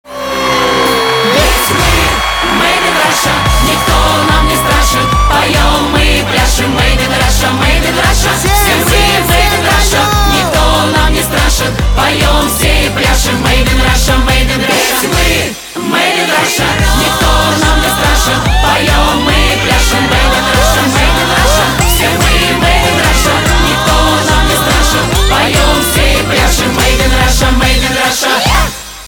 поп
патриотические
хлопки , веселые , позитивные , барабаны , балалайка